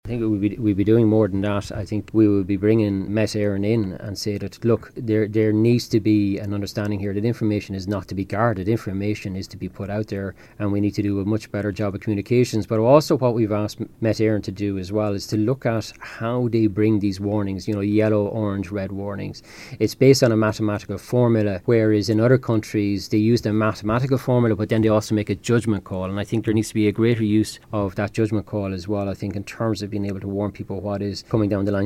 Minister for Housing James Browne has responded to the devastating flooding in Enniscorthy and surrounding areas acknowledging the distress faced by families and businesses across Wexford.